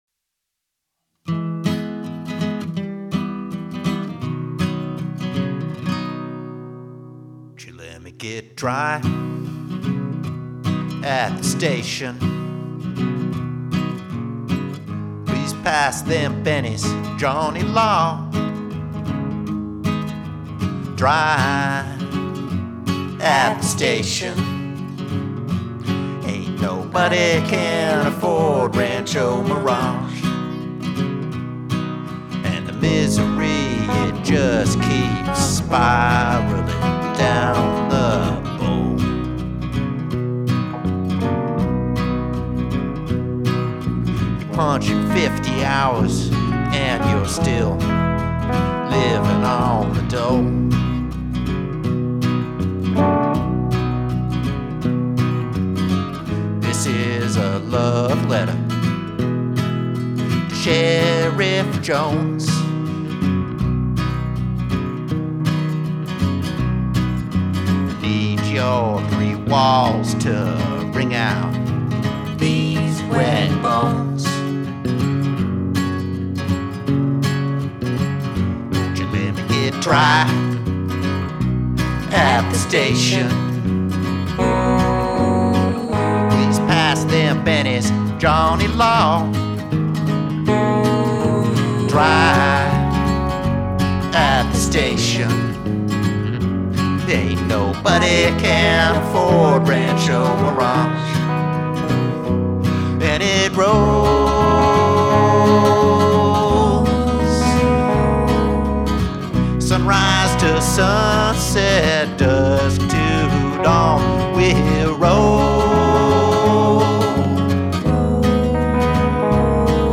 Lead vocals, acoustic and electric guitars, dobro
Background vocals
Bass